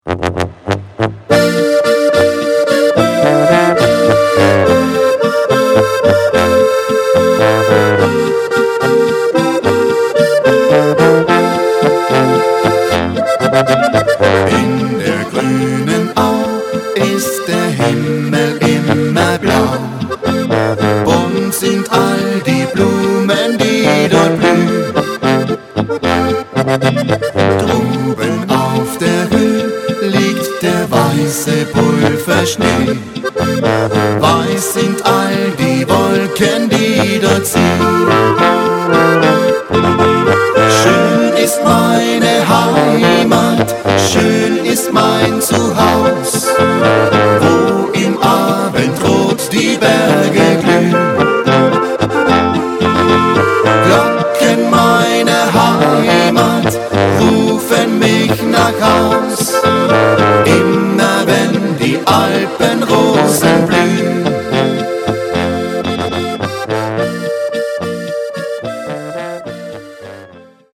VOLKSTÜMLICH